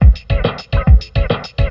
BigBeat
DRUMLOOP056_BIGBT_140_X_SC3(L).wav